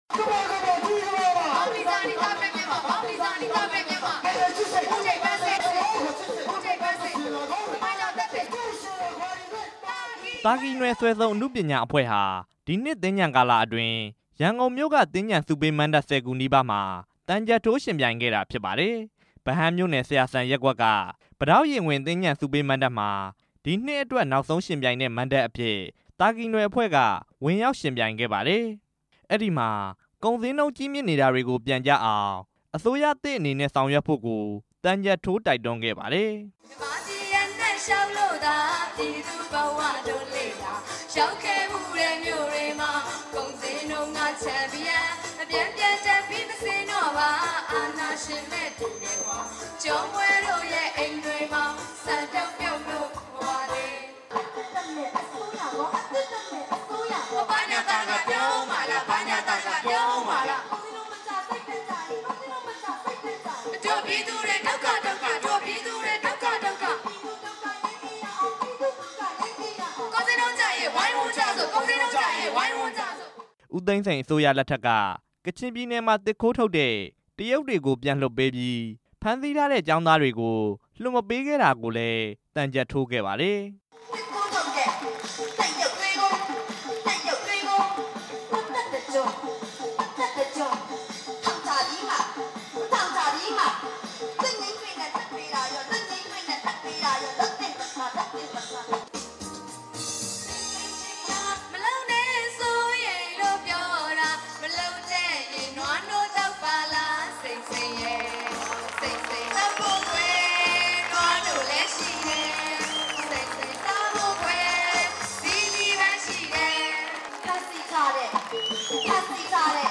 ရန်ကုန်တိုင်းဒေသကြီး ဗဟန်းမြို့နယ် ဆရာစံရပ်ကွက်က ပိတောက်ရင်ခွင် သြင်္ကန်ဆုပေးမဏ္ဍပ်မှာ သာကီနွယ် စွယ်စုံအနုပညာအဖွဲ့က မနေ့က ဧပြီလ ၁၆ ရက်နေ့ညမှာ သံချပ်ထိုး ယှဉ်ပြိုင်ခဲ့ပါတယ်။